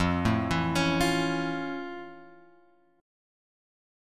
F Augmented Major 7th